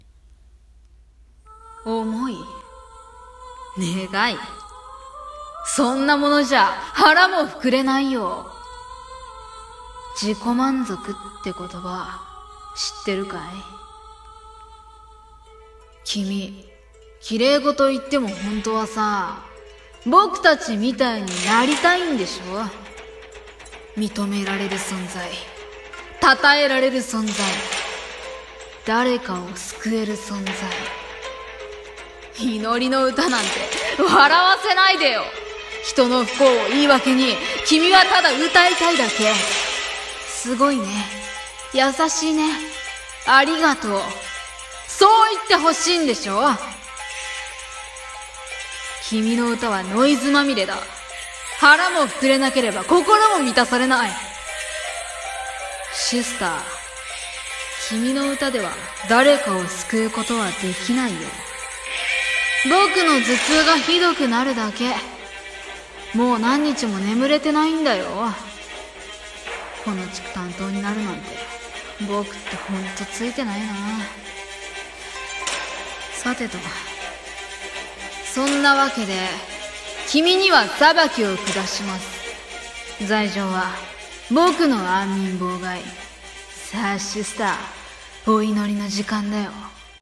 【声劇台本】